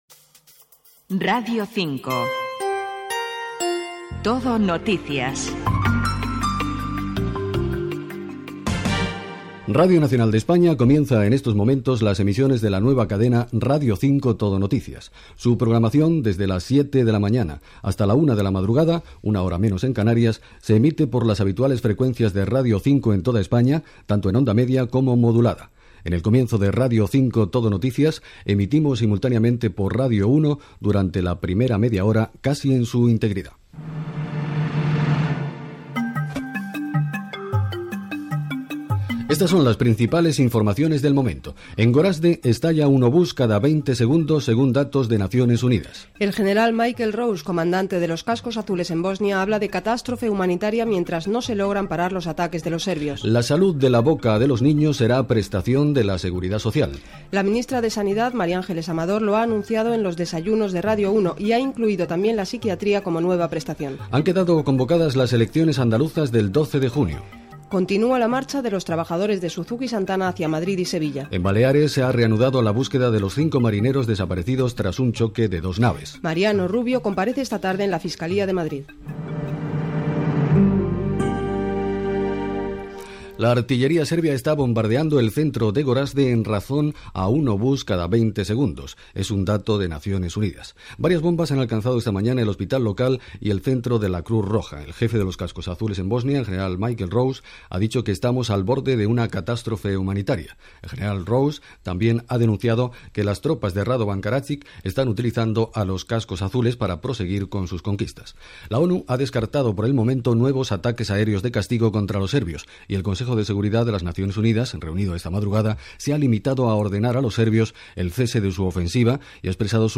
Fragment de l'emissió inaugural. Indicatiu, presentació, titulars, declaracions de la ministra de Sanitat Mariángeles Amador, eleccions andaluses, manifestació dels treballadors de Santana Motor, accident naval, etc. Titulars.
Informatiu